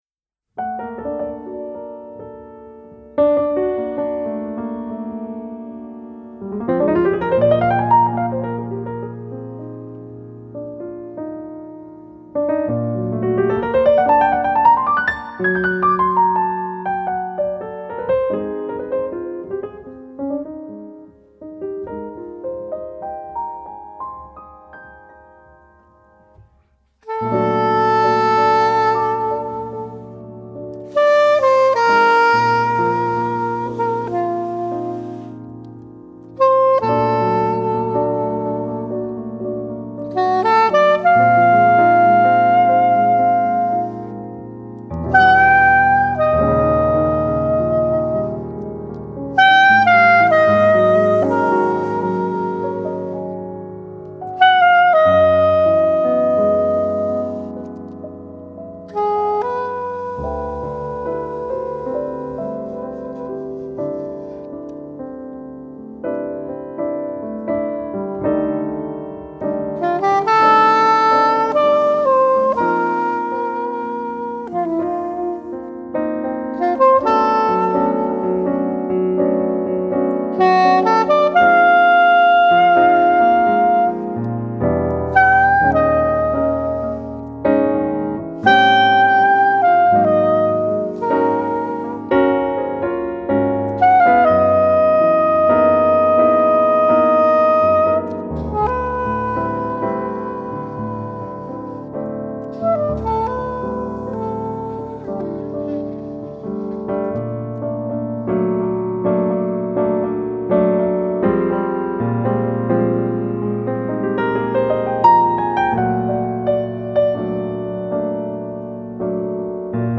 Soprano & tenorsaxophone
piano